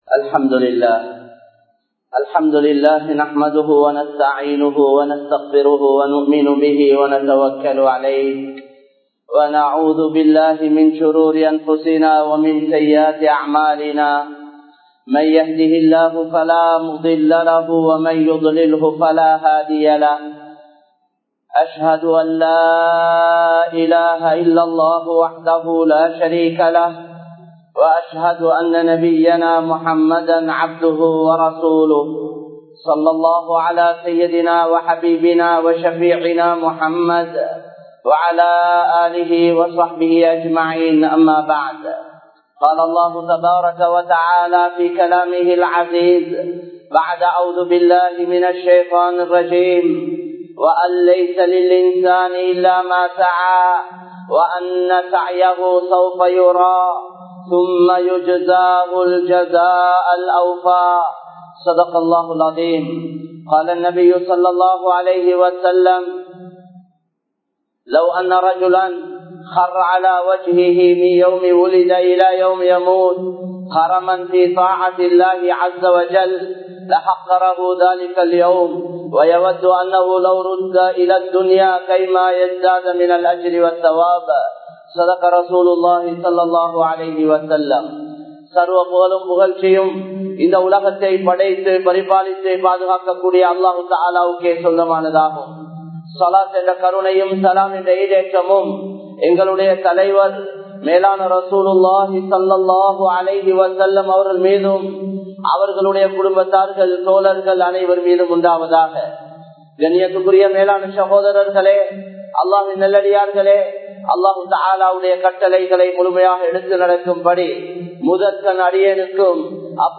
முதலில் நாம் மாறுவோம் | Audio Bayans | All Ceylon Muslim Youth Community | Addalaichenai
Kollupitty Jumua Masjith